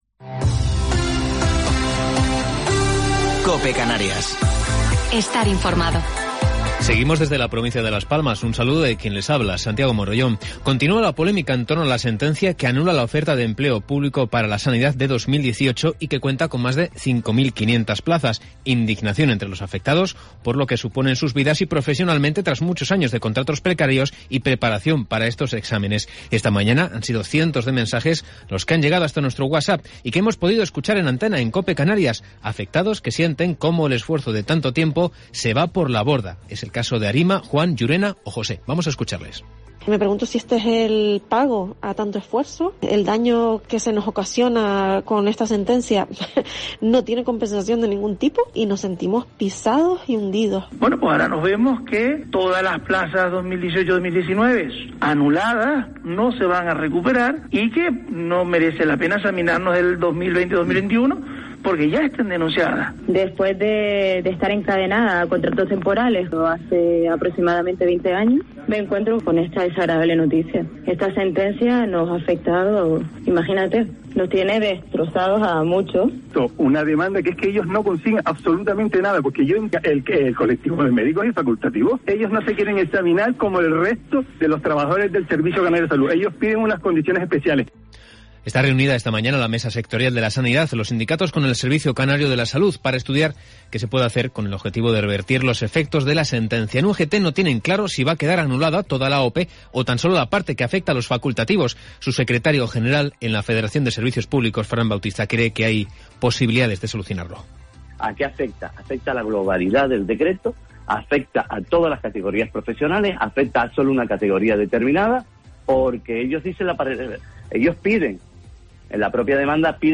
Informativo local 3 de Marzo del 2021